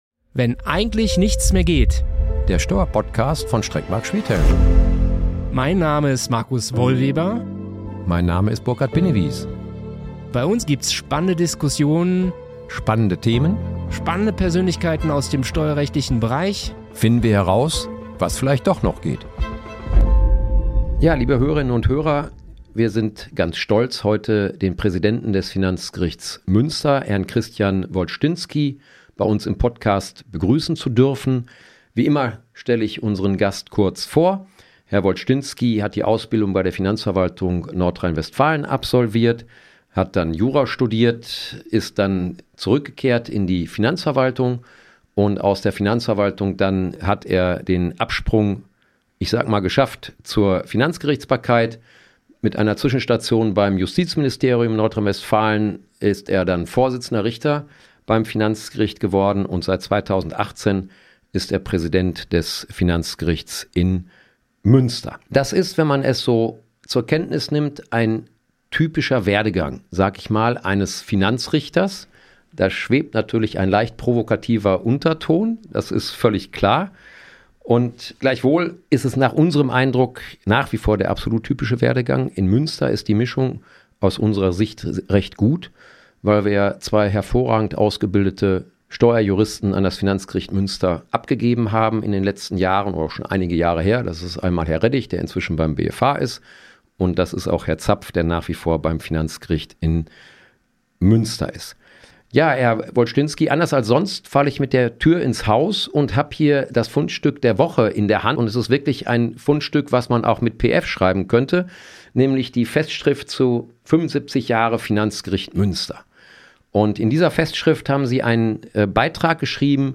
Wir diskutieren mit Herrn Wolsztynski, Präsident des Finanzgerichts Münster, über den modernen Finanzgerichtsprozess. Eine spannende Diskussion über Wunsch und Wirklichkeit des Finanzgerichtsprozesses als rechtstaatliche Dienstleistung.